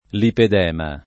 vai all'elenco alfabetico delle voci ingrandisci il carattere 100% rimpicciolisci il carattere stampa invia tramite posta elettronica codividi su Facebook lipedema [ liped $ ma ] o lipoedema [ lipoed $ ma ] s. m. (med.); pl. ‑mi